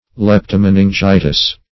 Leptomeningitis \Lep`to*men`in*gi"tis\ (l[e^]p`t[-o]*m[e^]n`[i^]n*j[imac]"t[i^]s)
leptomeningitis.mp3